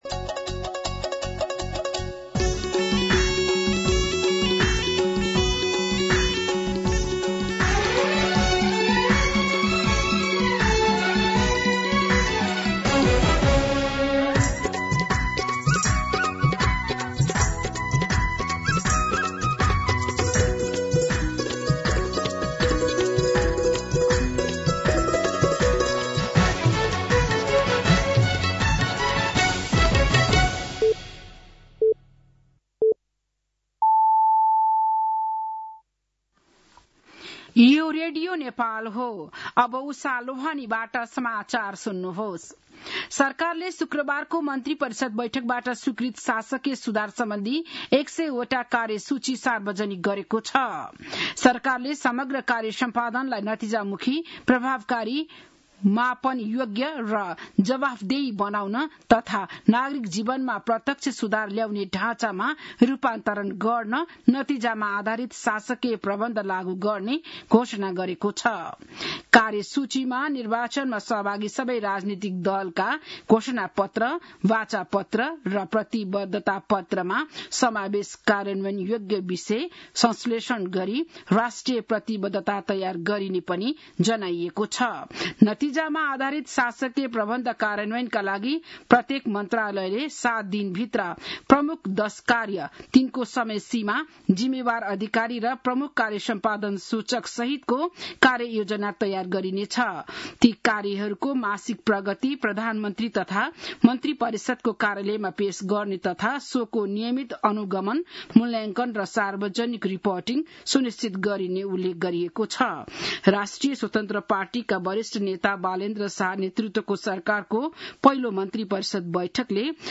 An online outlet of Nepal's national radio broadcaster
बिहान ११ बजेको नेपाली समाचार : १५ चैत , २०८२